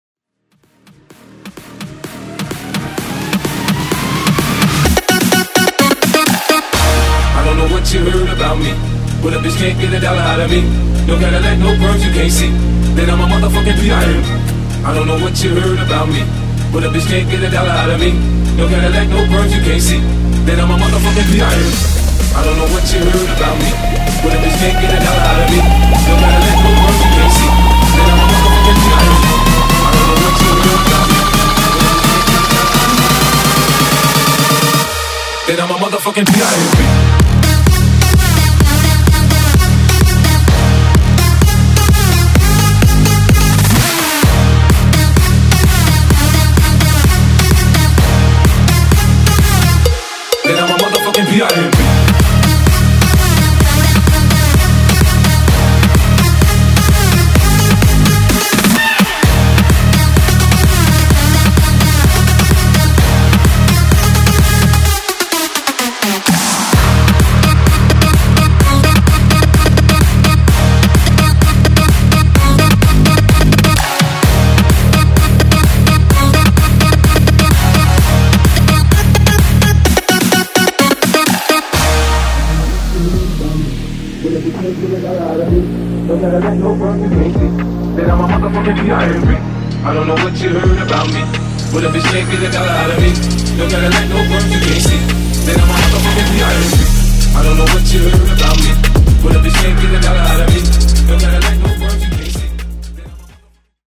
Genre: 80's
Clean BPM: 119 Time